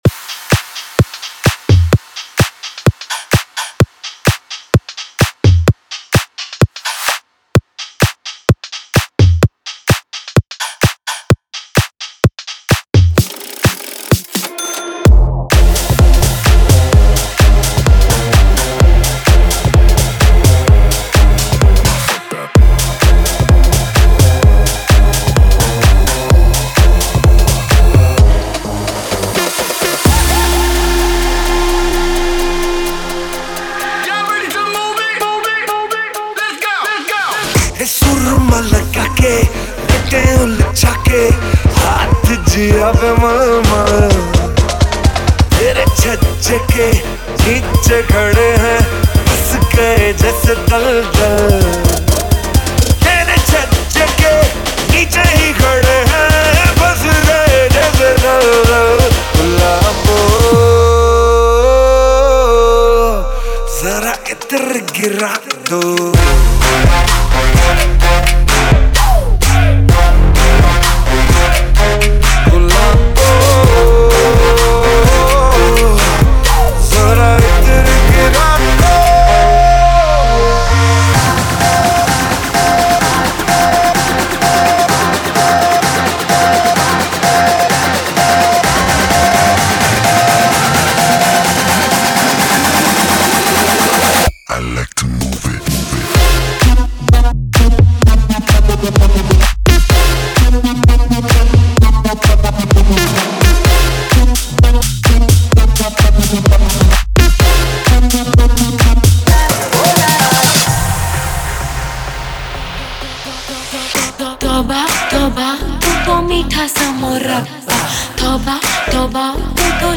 Bollywood · Mashup